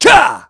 Clause_ice-Vox_Attack4.wav